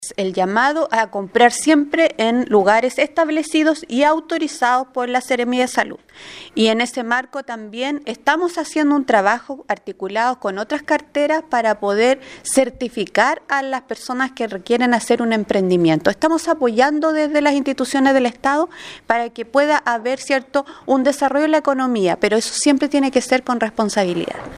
La Seremi de Salud Karin Solis formuló un llamado a la población a no adquirir productos que tengas dudosa procedencia o resolución sanitaria qiue garantice su calidad, especialmente aquellos que se comercializan en lugares como ferias libres y mercados.